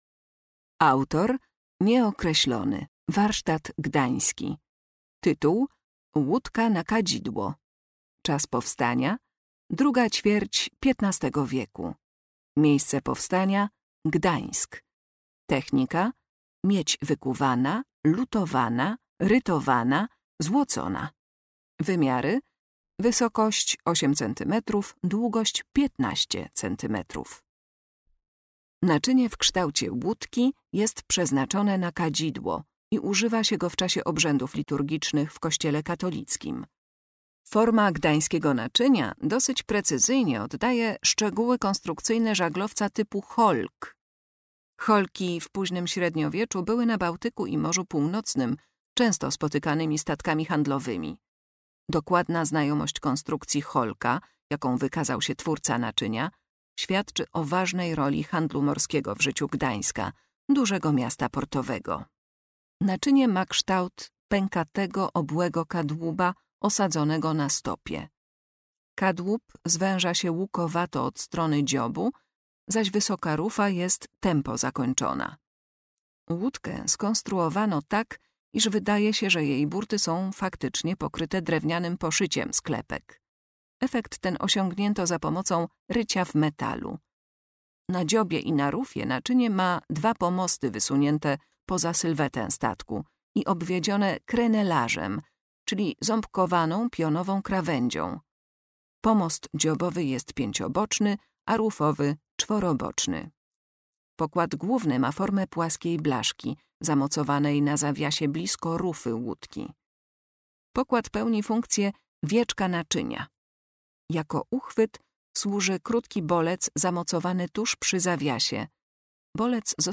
Audiodeskrypcje do wystawy stałej w Oddziale Sztuki Dawnej